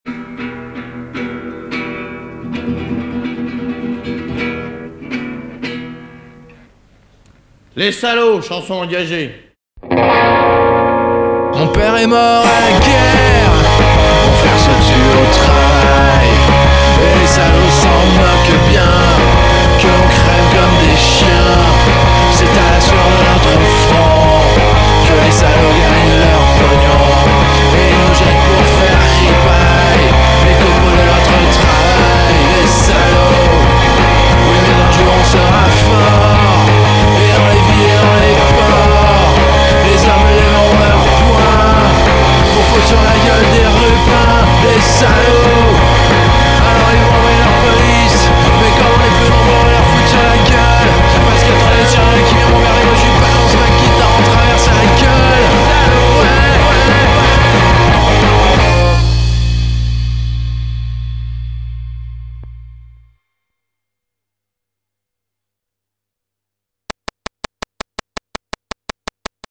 (démo)